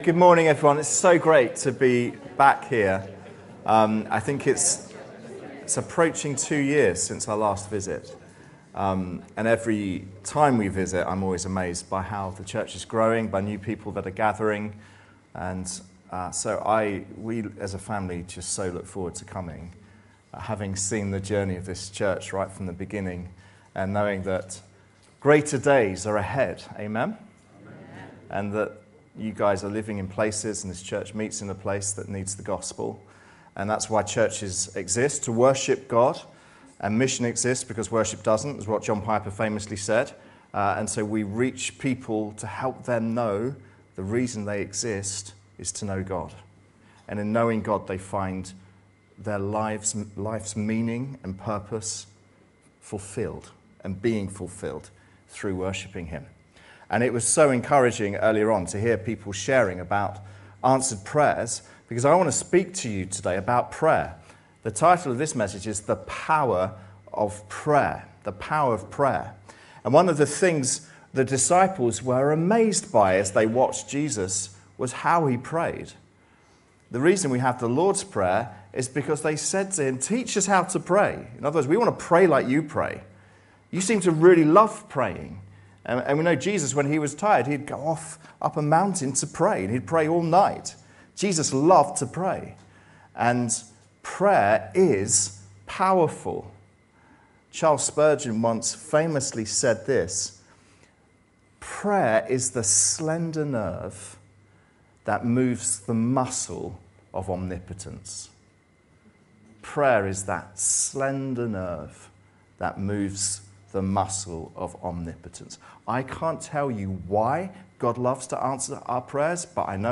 This sermon through the story of Hezekiah reminds us that God powerfully answers the prayers of his children.